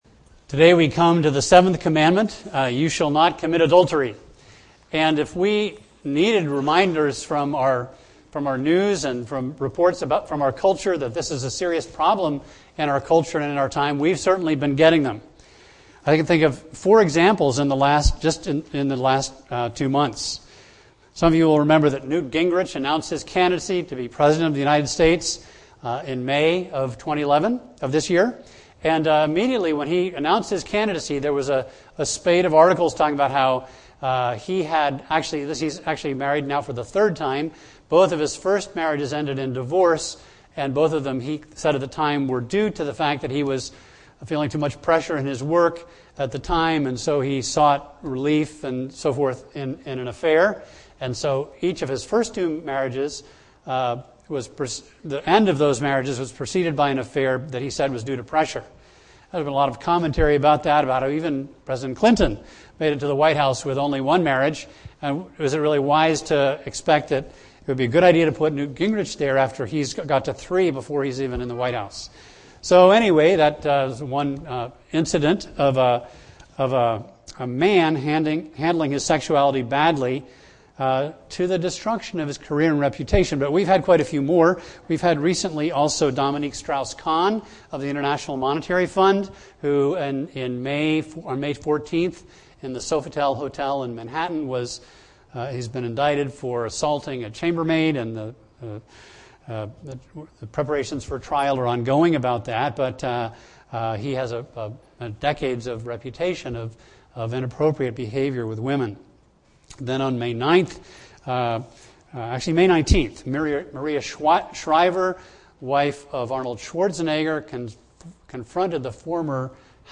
A message from the series "10 Commandments."